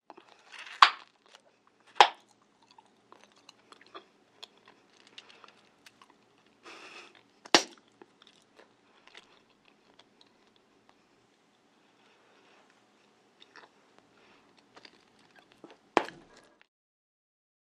звук жевания жвачки